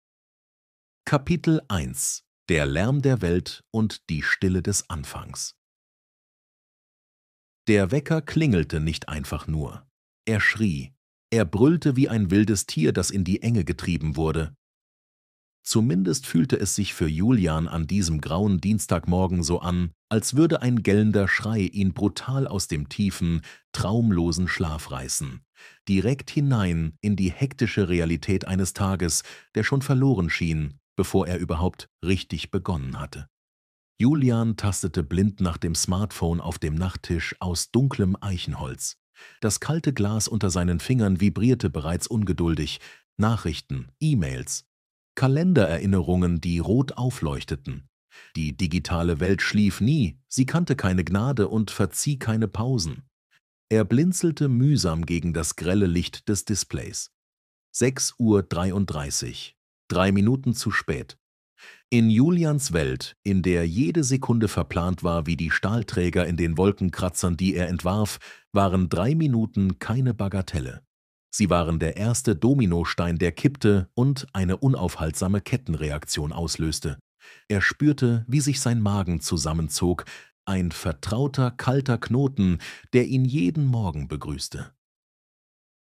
84 Minuten, mp3 Format, Sachbuchroman, € 5,99
Hörprobe - Der Tag, an dem das Chaos endlich schwieg